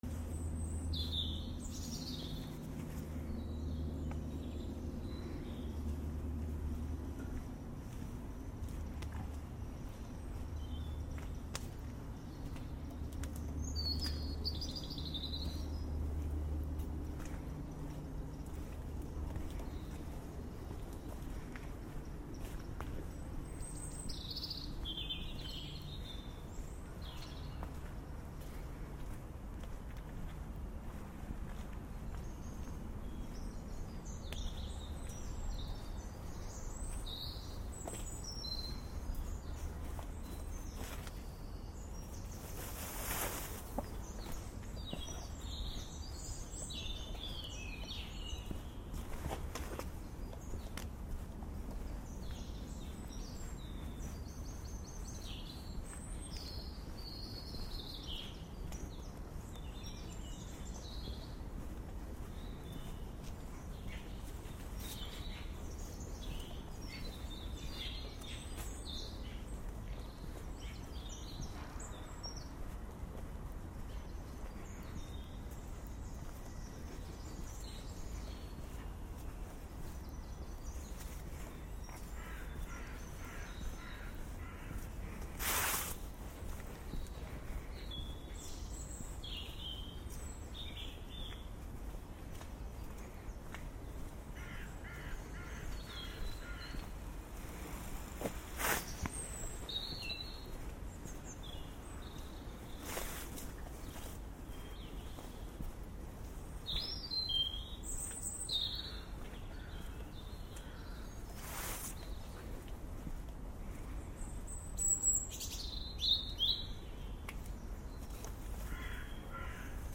Dusk Walk with Birdsong 4 Jan 2022